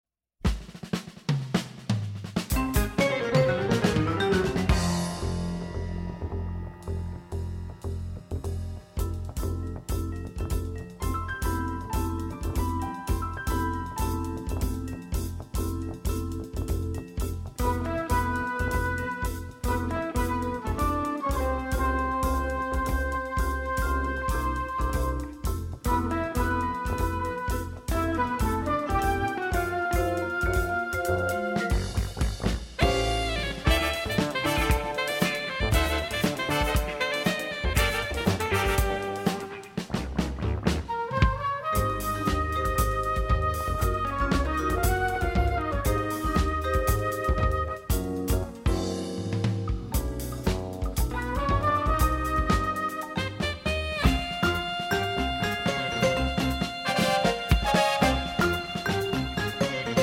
vibraphone
drums and percussion
guitar
bass